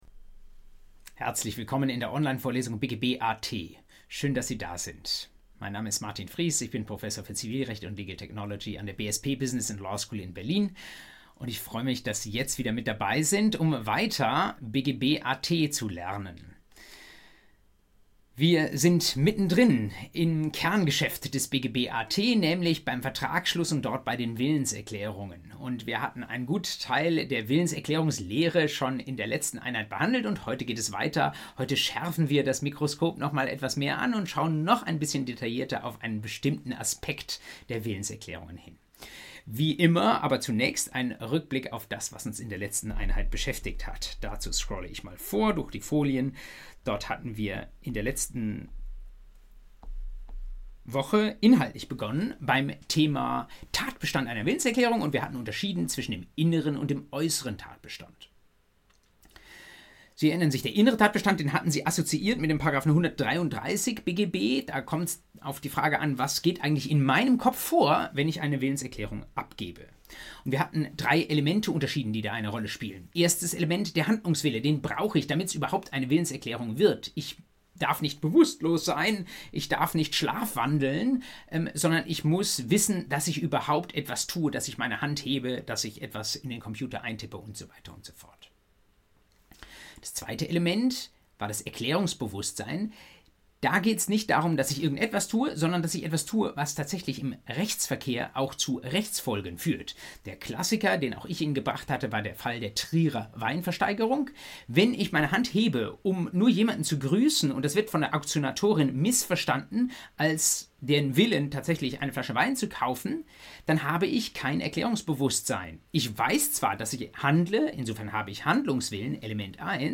BGB AT Einheit 4: Abgabe und Zugang von Willenserklärungen ~ Vorlesung BGB AT Podcast